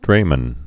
(drāmən)